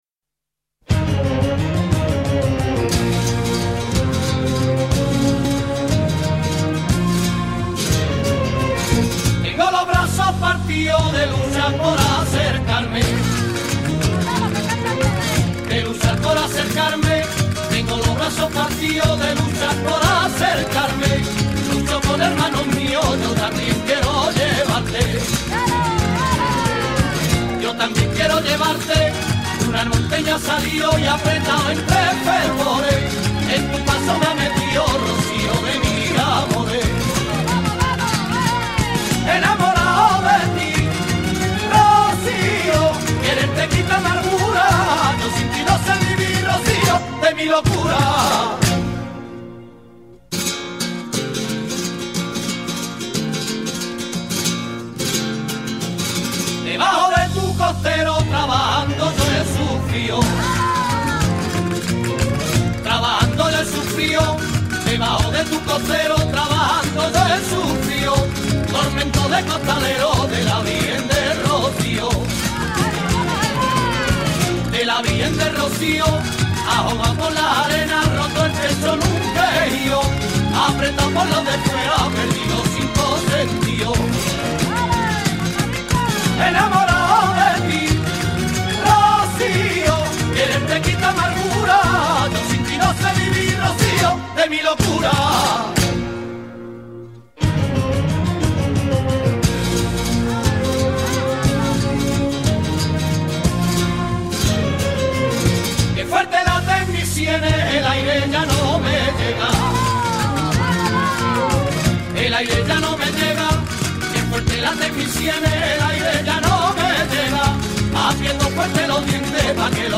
preciosa m�sica andaluza